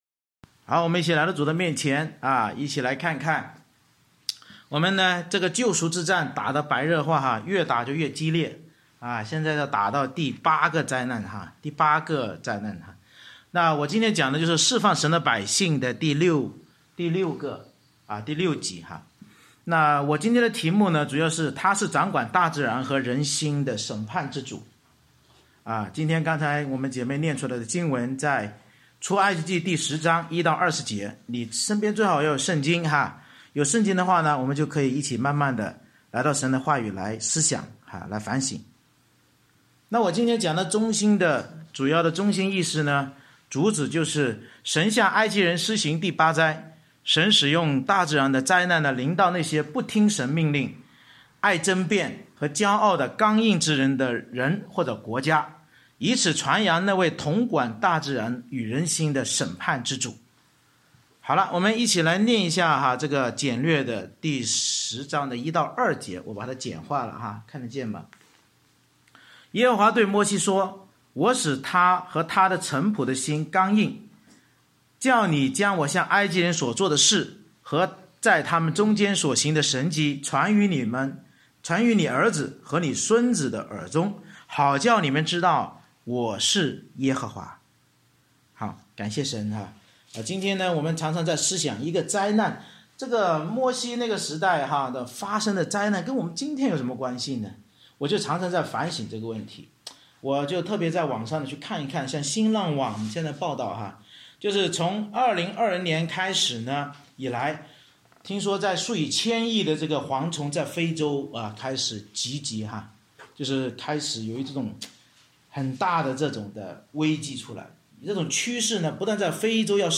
《出埃及记》讲道系列 Passage